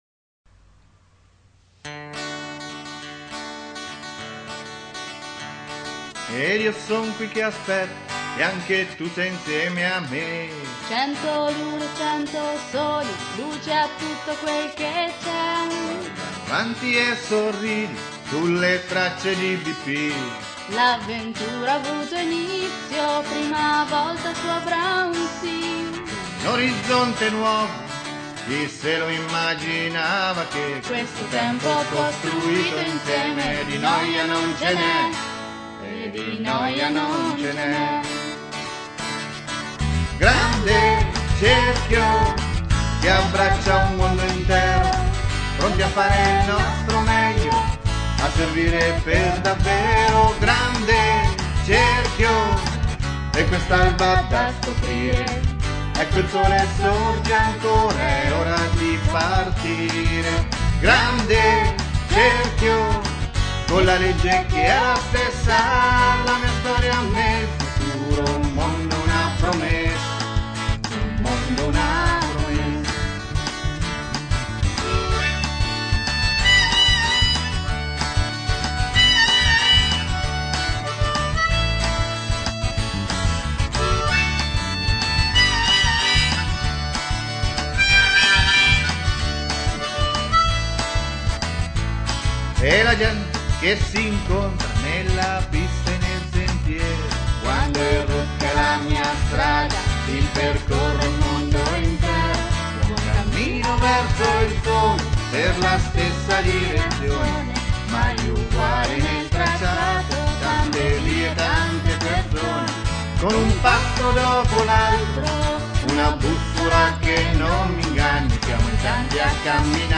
Canto del centenario